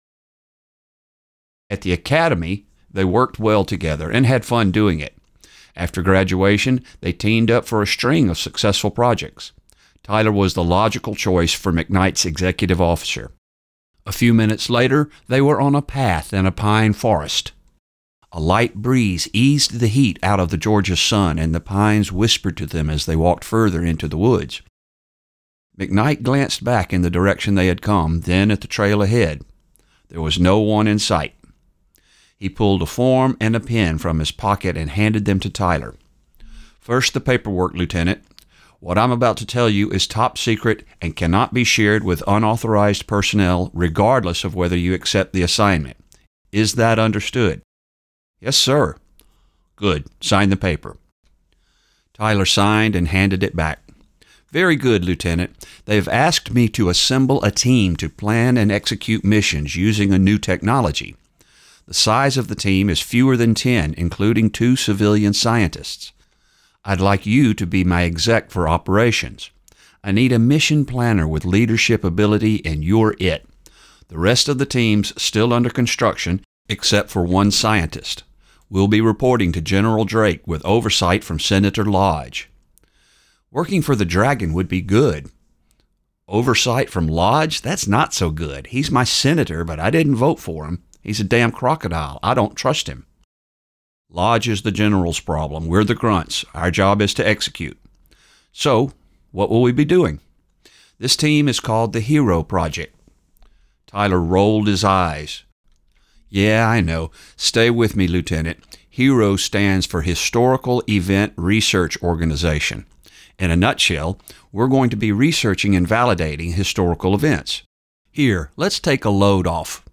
Audiobook Sample, Book Promo, Science Fiction, Series, Time Travel